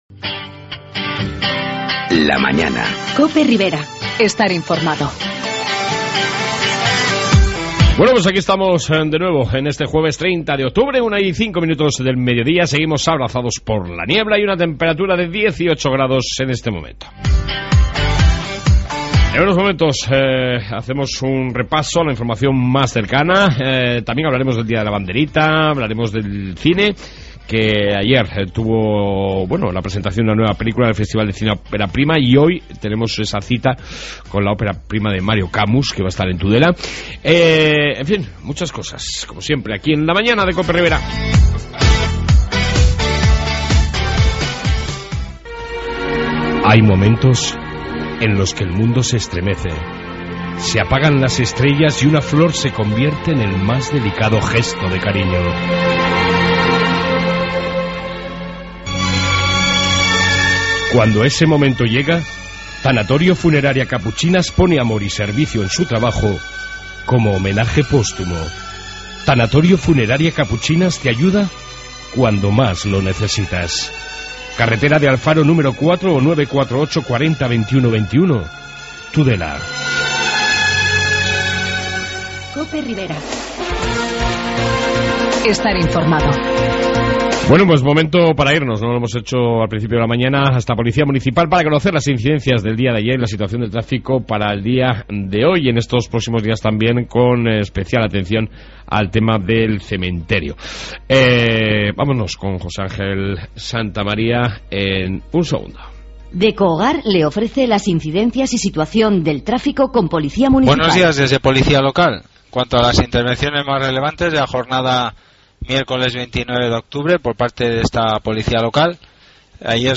Redacción digital Madrid - Publicado el 30 oct 2014, 18:08 - Actualizado 14 mar 2023, 05:14 1 min lectura Descargar Facebook Twitter Whatsapp Telegram Enviar por email Copiar enlace En esta 2 parte Informativo ribero que incluye entrevista sobre el día de la banderita en tudela y festival opera prima...